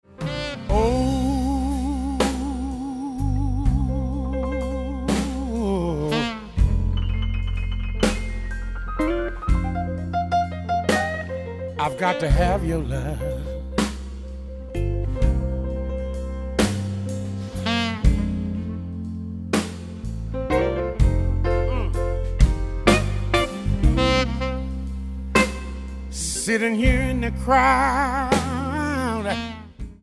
Genre: Blues
This is a pure analog tape recording.